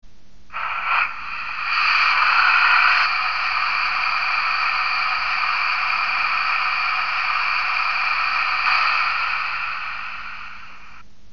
BR 18.4 mit ESU Loksound mfx Sound 1:
Sound 1 hört sich nach Zylinderabdampf an,